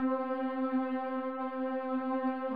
Rusty Strings.wav